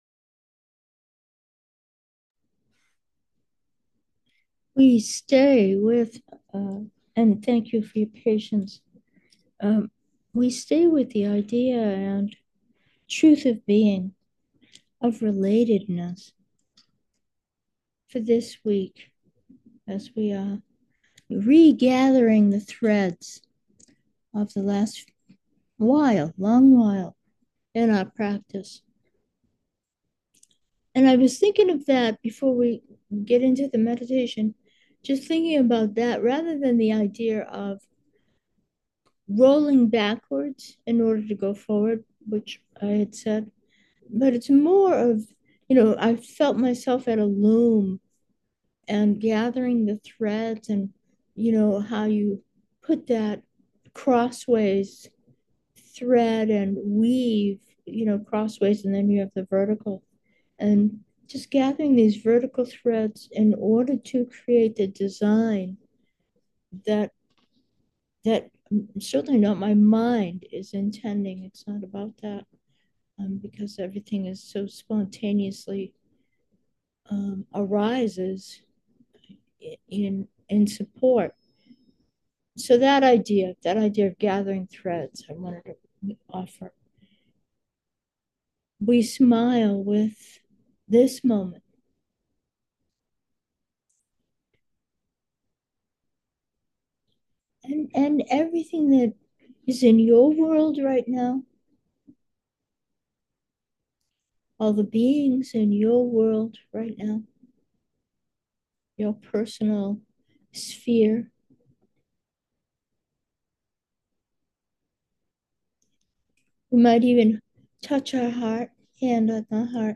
Meditation: metta 2